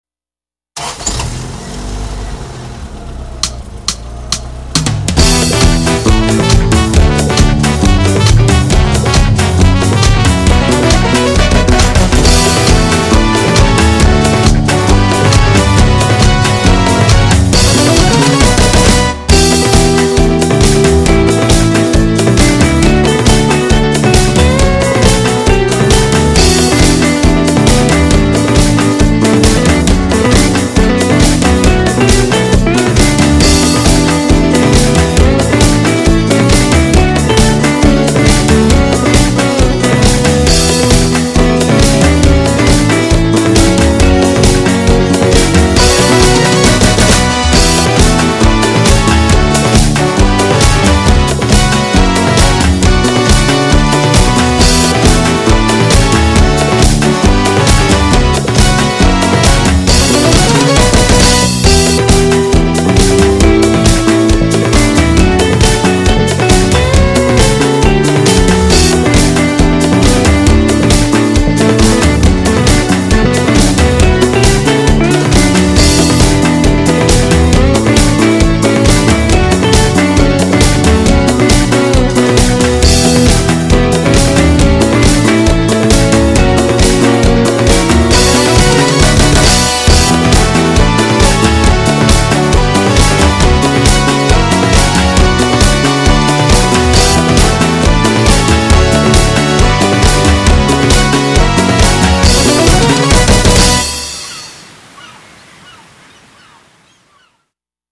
라틴이 약간 기미된(?) Summer 퓨젼곡입니다